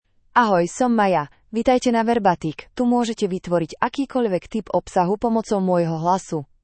MayaFemale Slovak AI voice
Maya is a female AI voice for Slovak (Slovakia).
Voice sample
Listen to Maya's female Slovak voice.
Female
Maya delivers clear pronunciation with authentic Slovakia Slovak intonation, making your content sound professionally produced.